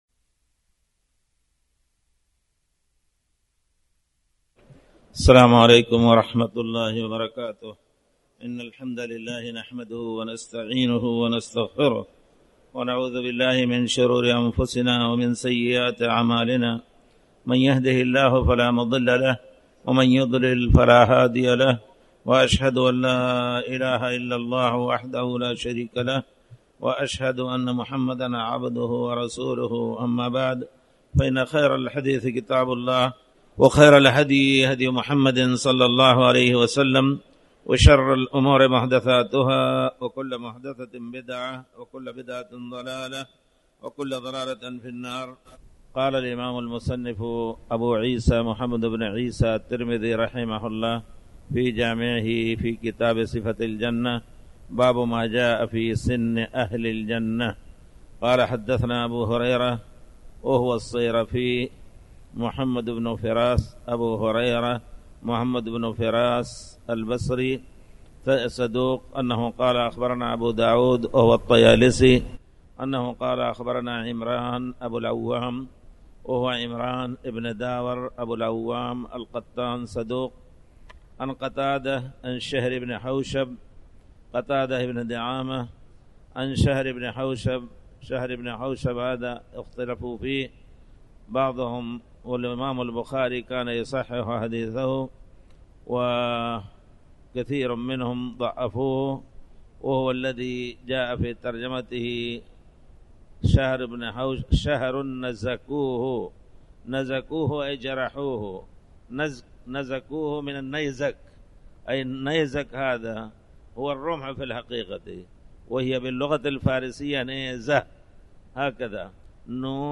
تاريخ النشر ٢٩ جمادى الآخرة ١٤٣٩ هـ المكان: المسجد الحرام الشيخ